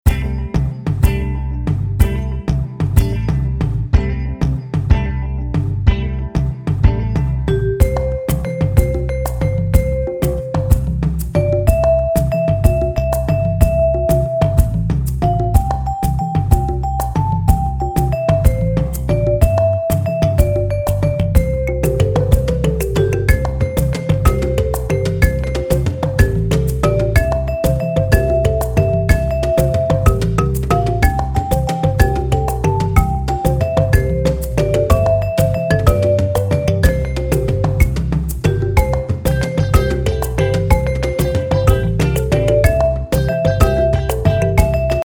-:  mp3 Mp3 Instrumental Song Track